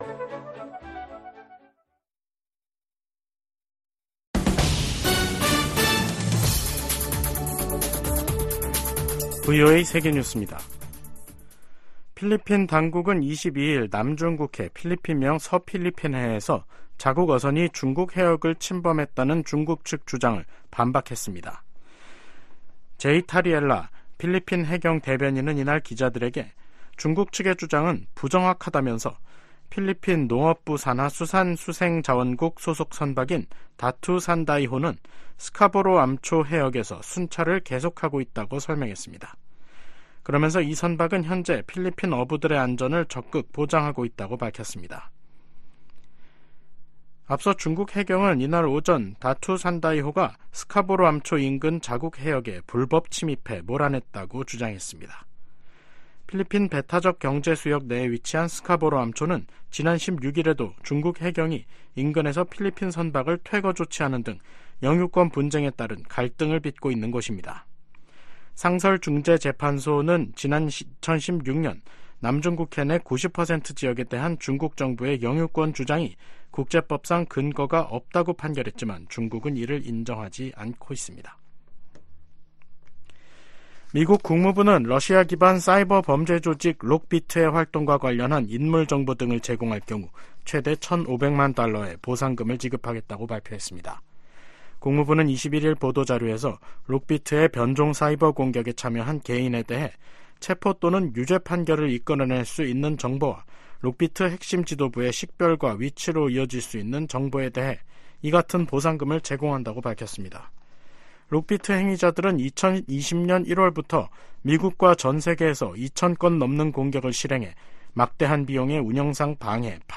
VOA 한국어 간판 뉴스 프로그램 '뉴스 투데이', 2024년 2월 22일 3부 방송입니다. 미 국무부가 북한 대량살상무기·탄도미사일에 사용될 수 있는 민감한 품목과 기술 획득을 막는데 모든 노력을 기울일 것이라고 밝혔습니다. 미 하원에서 우크라이나 전쟁 발발 2주년을 맞아 러시아·북한·중국·이란 규탄 결의안이 발의됐습니다. 한국 정부는 북한과 일본 간 정상회담 관련 접촉 움직임에 관해, 한반도 평화 유지 차원에서 긍정적일 수 있다는 입장을 밝혔습니다.